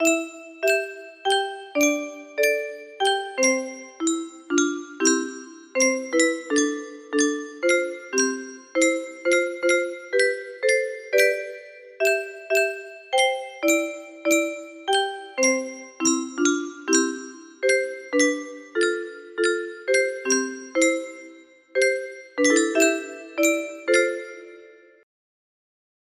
Unknown Artist - Mystery music box melody